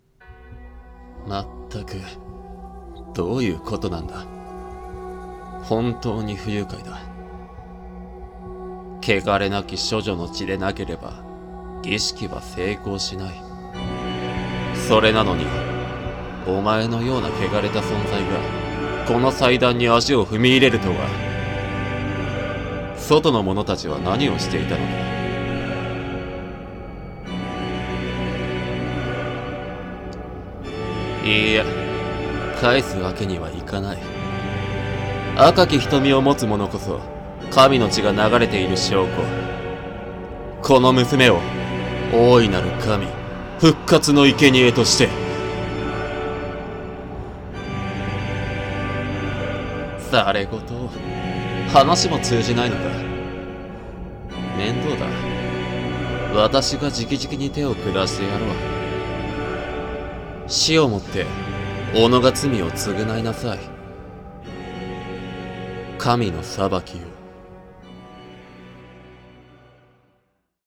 【声劇】神の裁きを